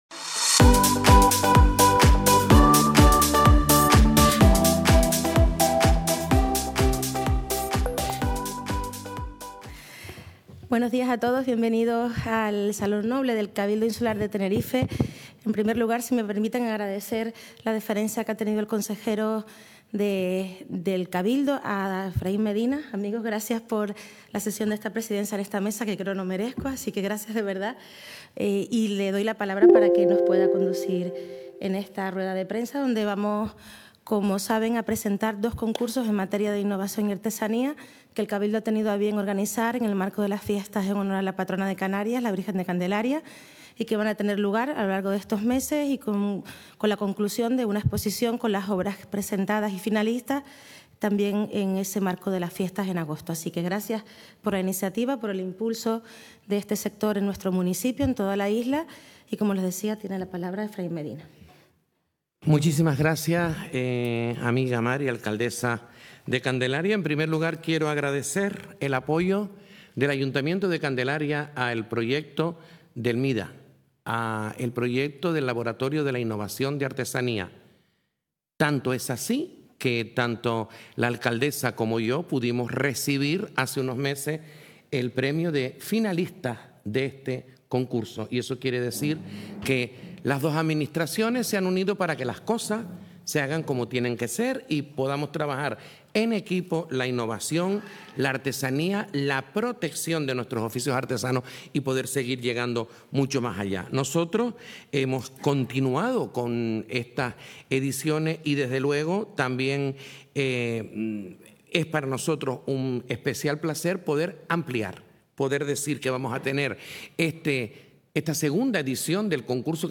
El Cabildo de Tenerife, a través de la Empresa Insular de Artesanía con la colaboración del Ayuntamiento de Candelaria renuevan su compromiso con la innovación y la excelencia artesana. El Salón Noble acogió (hoy), la rueda de prensa de...